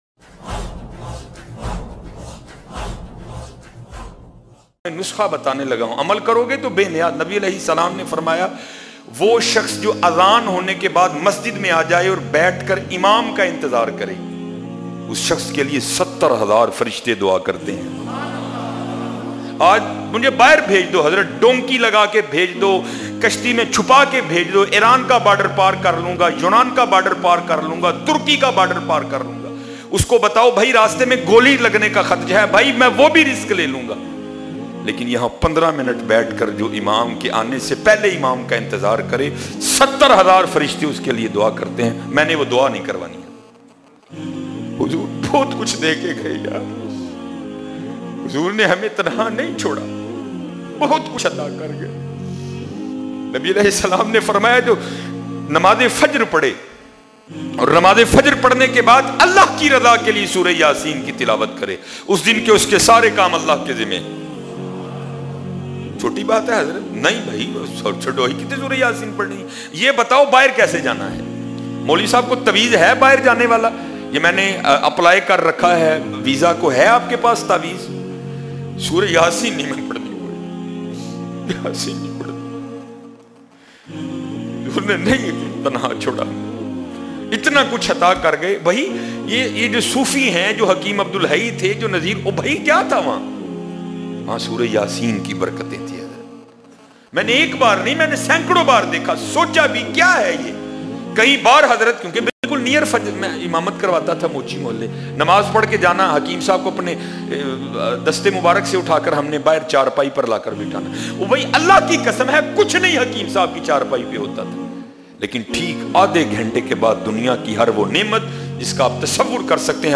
70 hazar frishton ki dua bayan mp3 download